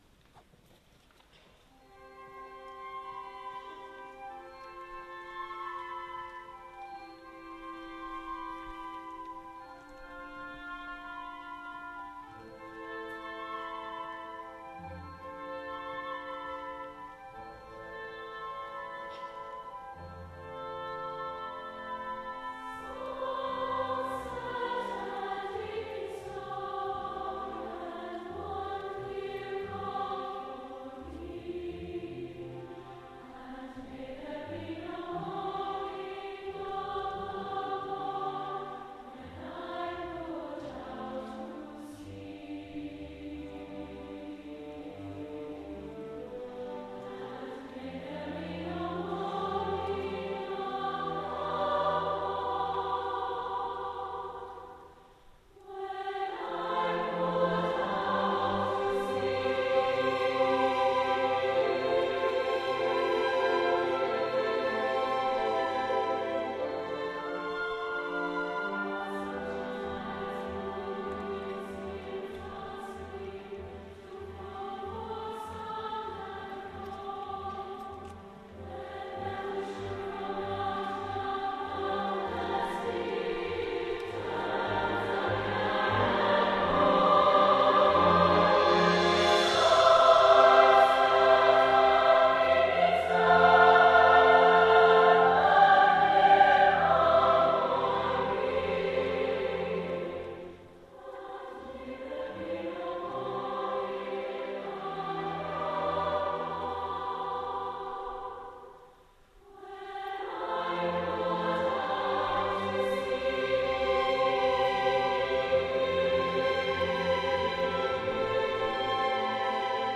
a MP3 file of Crossing the Bar (SSAA and orchestra).